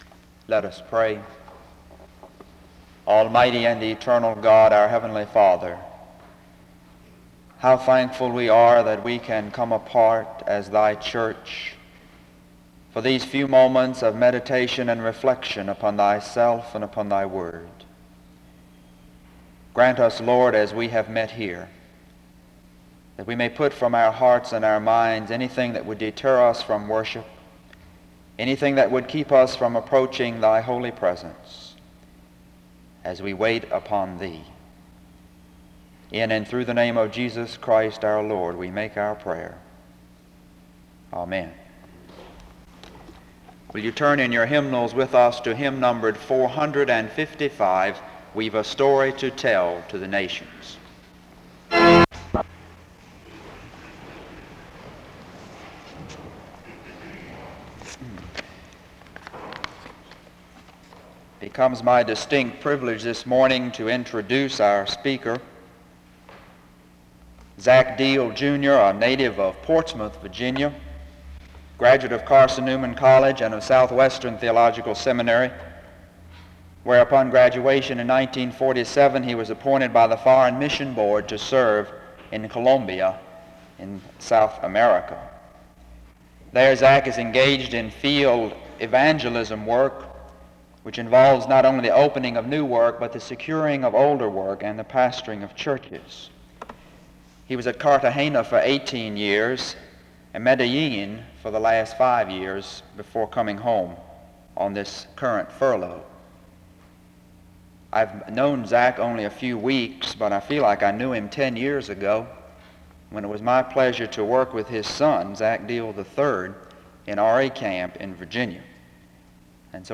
The service begins with a prayer (0:00-0:42).
Location Wake Forest (N.C.)